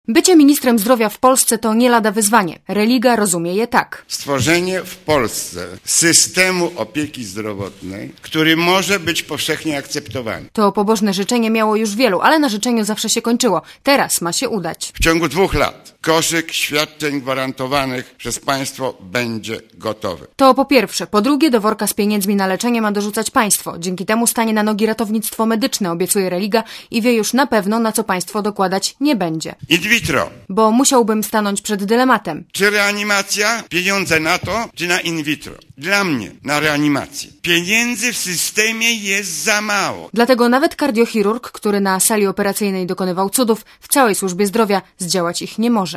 Budowa nowego systemu opieki zdrowotnej potrwa co najmniej siedem - powiedział na konferencji prasowej minister zdrowia Zbigniew Religa.